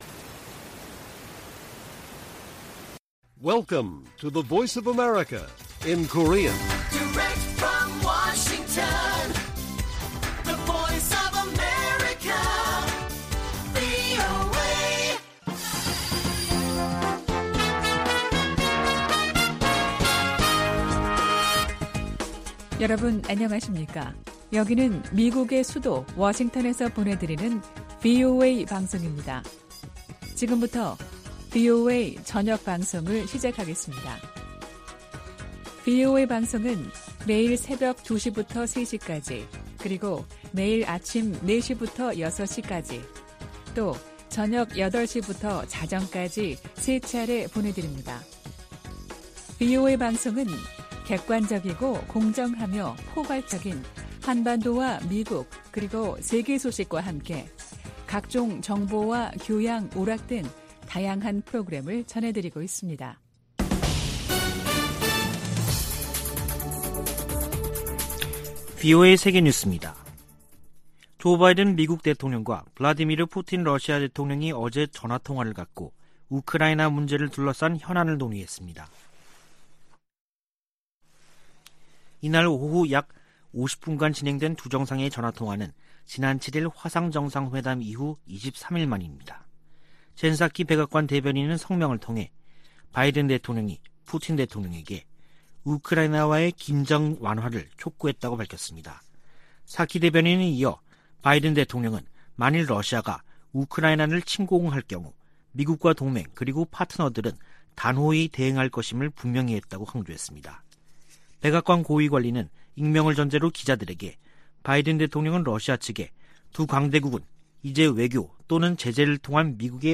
VOA 한국어 간판 뉴스 프로그램 '뉴스 투데이', 2021년 12월 31일 1부 방송입니다. 미국과 한국이 종전선언 문안에 합의한 것으로 알려지면서 북한과의 협의로 진전될지 주목됩니다. 2021년 미국은 7년 만에 가장 적은 독자 대북 제재를 부과했습니다. 북한의 곡물생산량이 지난해 보다 증가했지만 식량난은 여전한 것으로 분석됐습니다.